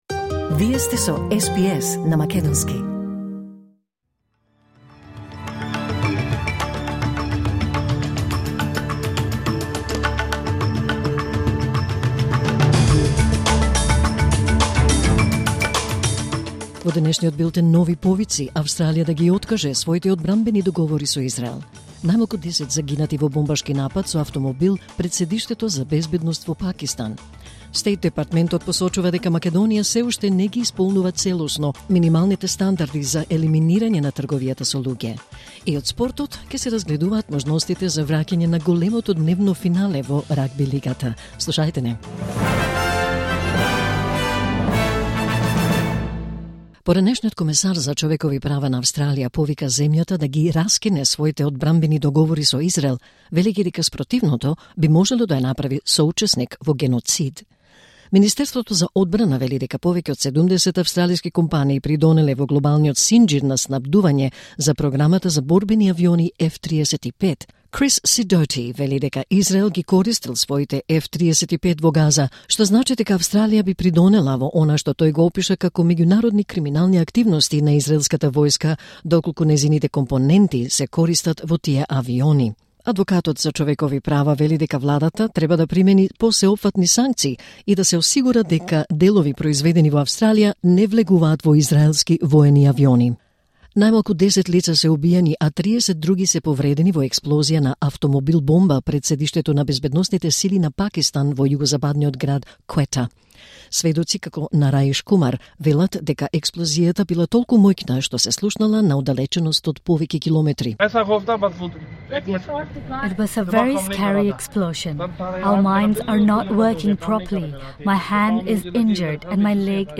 Вести на СБС на македонски 1 октомври 2025